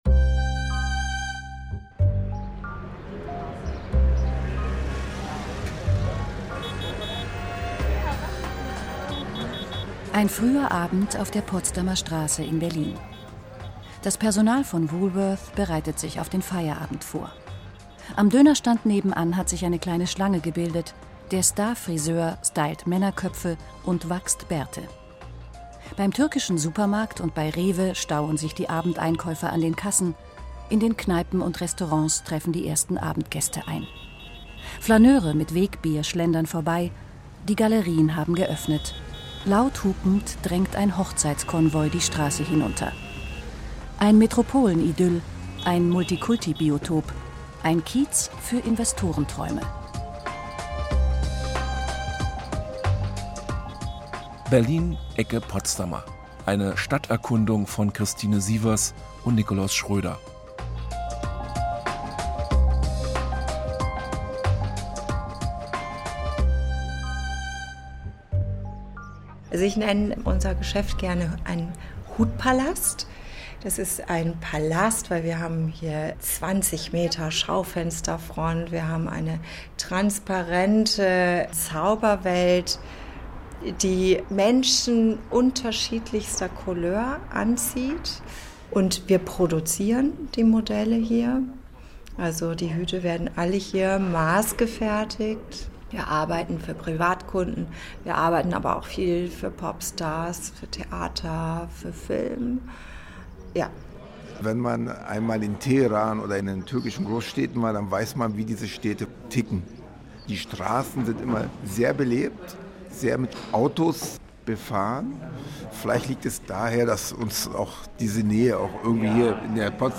Berlin, Ecke Potsdamer. Eine Stadterkundung